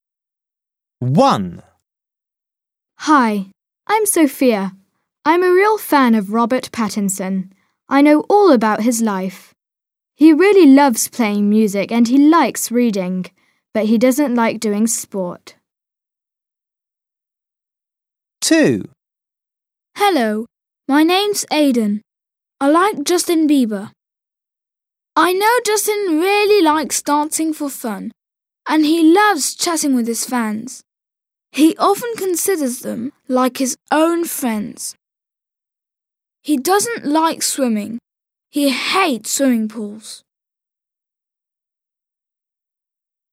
Ecoute deux fans qui parlent de ce que Robert Pattinson et Justin Bieber aiment ou n’aiment pas faire.